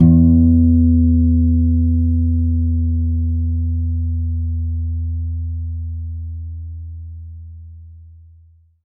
52-str06-abass-e2.aif